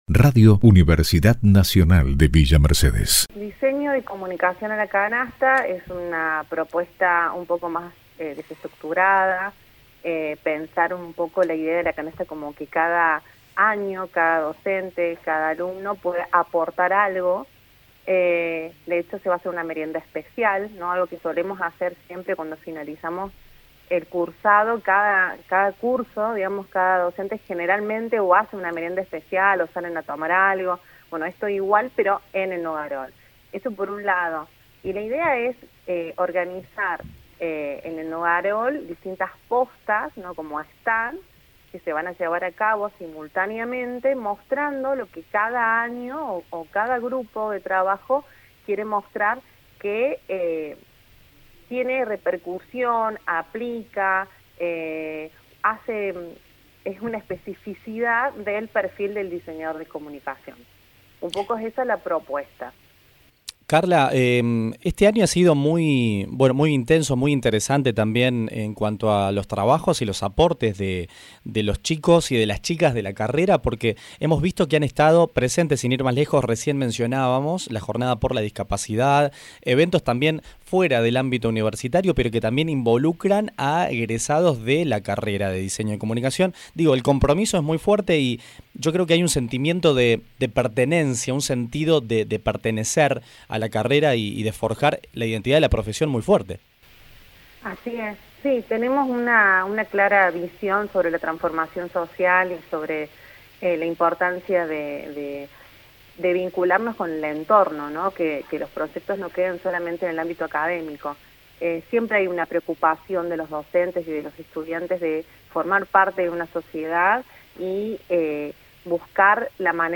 dialogó en radio UNViMe y brindó más detalles acerca de esta actividad.